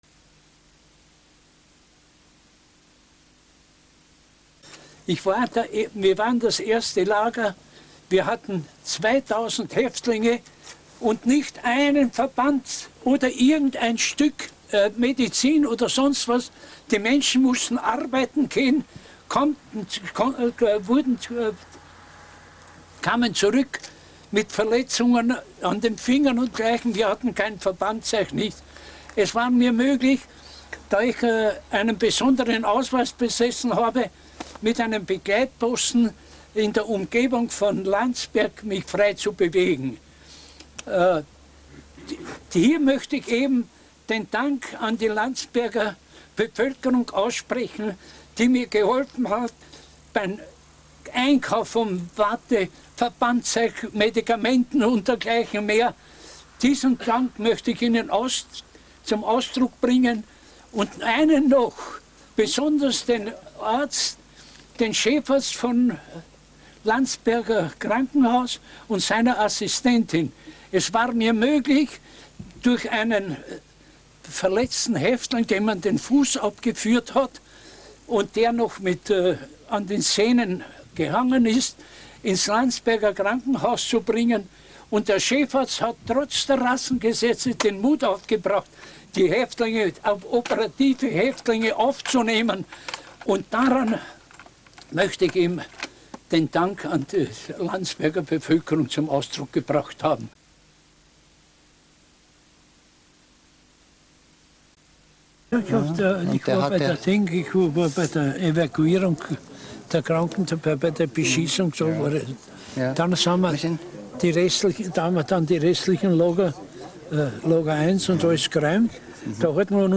Zeitzeugenbericht im Juli 1988 auf dem ehemaligen KZ-Lager Kaufering VII.
Bei der Einweihung des Mahnmals für die Opfer des KZ-Lagers Kaufering VII auf der Europäischen Holocaustgedenkstätte im Juli 1988 kam es zu vielen bewegenden Begegnungen.
zurück zu: Original-Tondokumente von Zeitzeugen